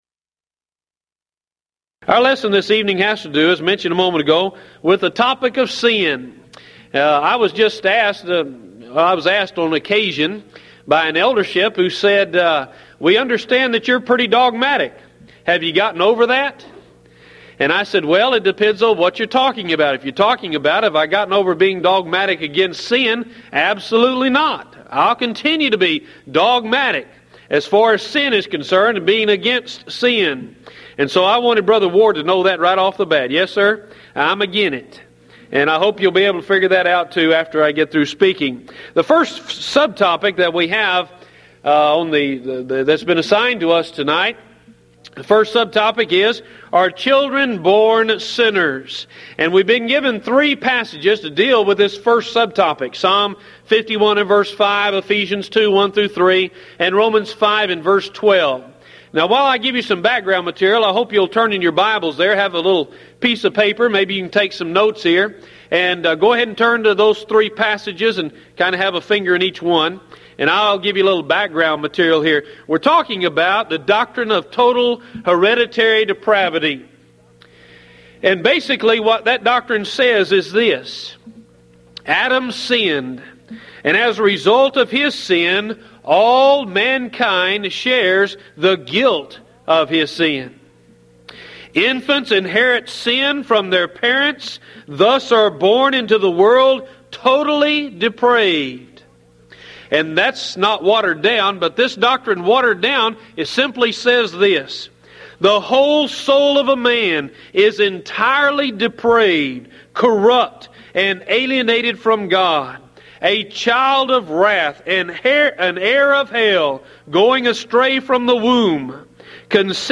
Event: 1995 Mid-West Lectures
lecture